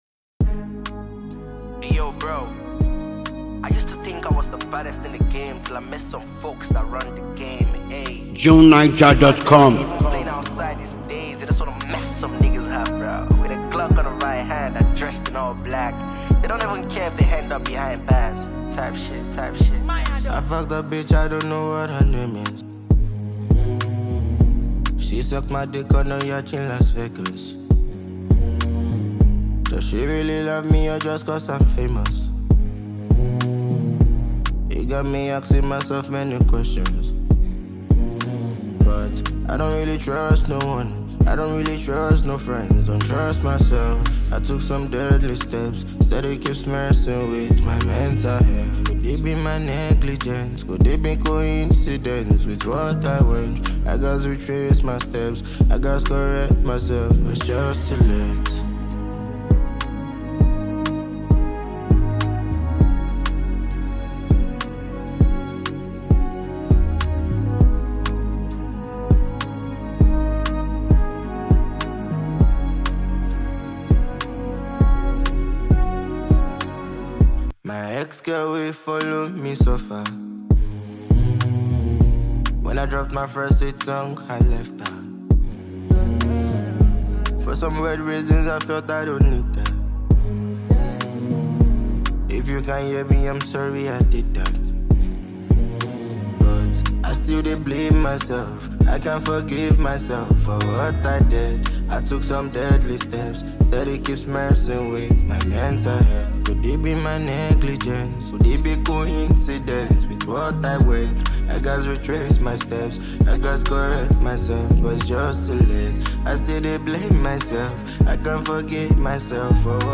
searing and opulent new song